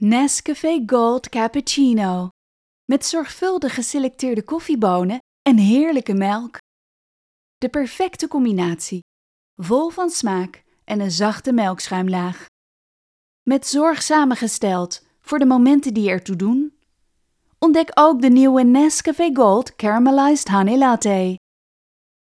Op zoek naar een vrouwelijke voice-over met energie en overtuiging?
referentie-demo